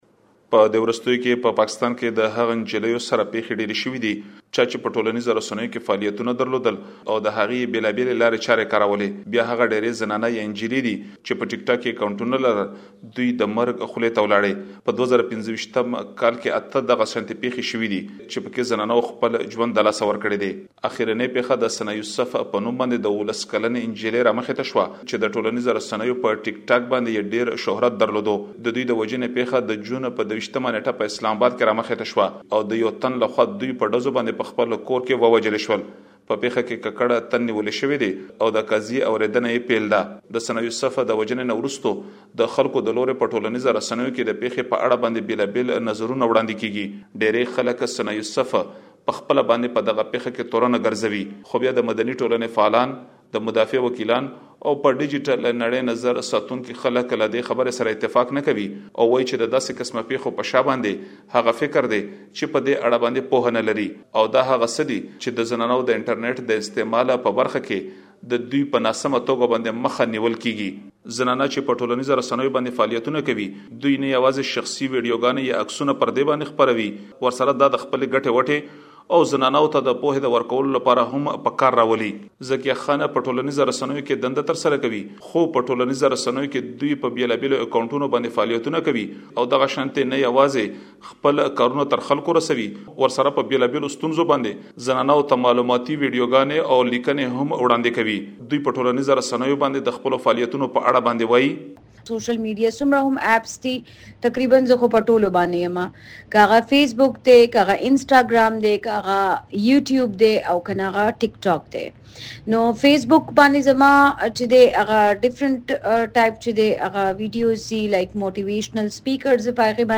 رپوټ‌